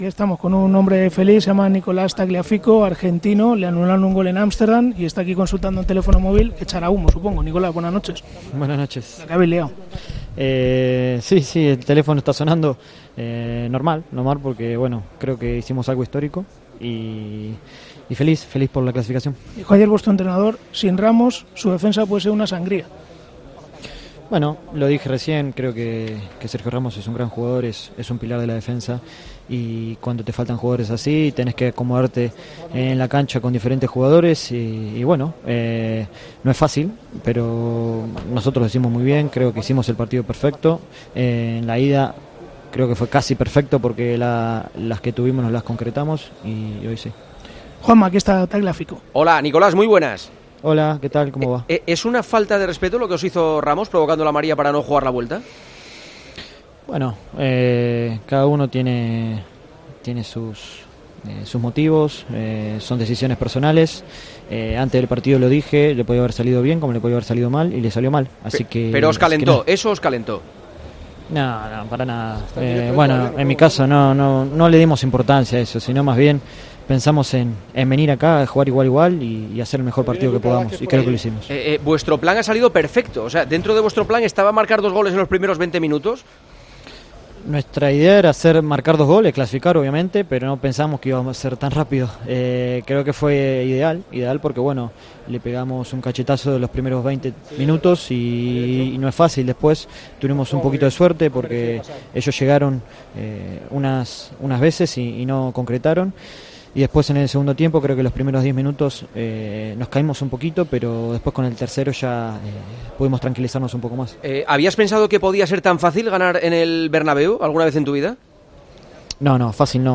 AUDIO: Entrevista al defensa del Ajax: "Hicimos algo histórico.